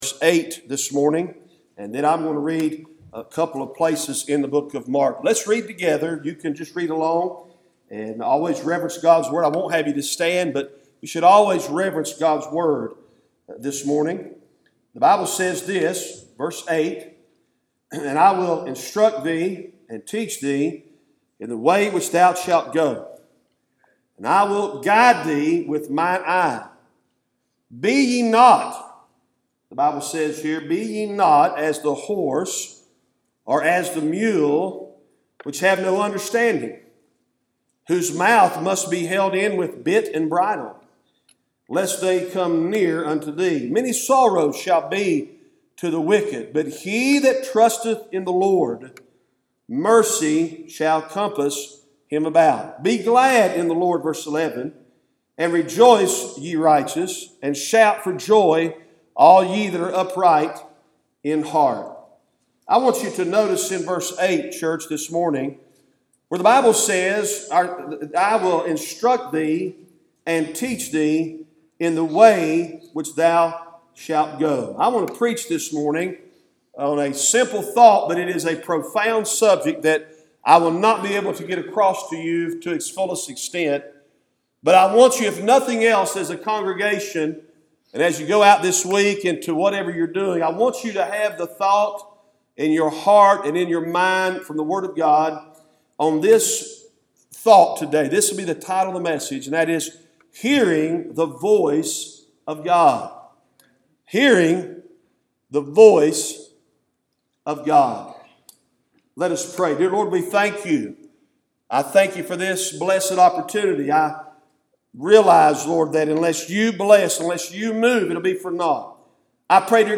Cooks Chapel Baptist Church Sermons